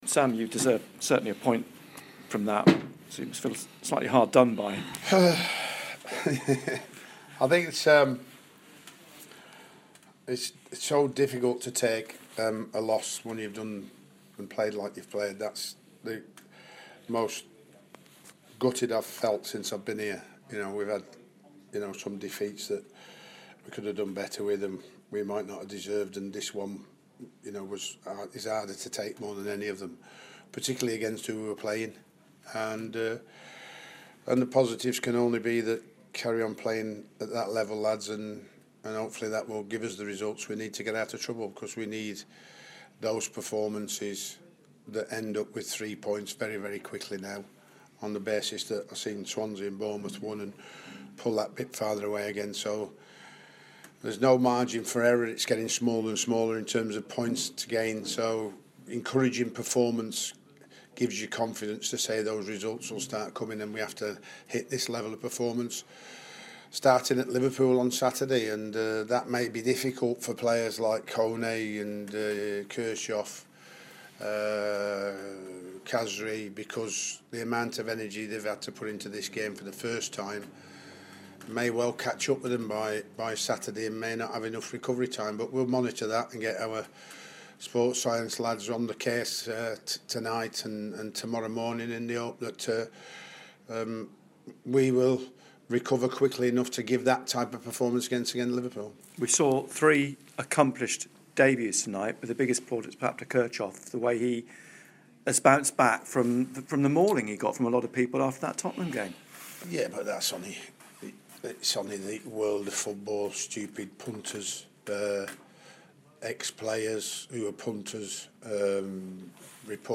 Sunderland manager Sam Allardyce talks to BBC Newcastle about their defeat by Manchester City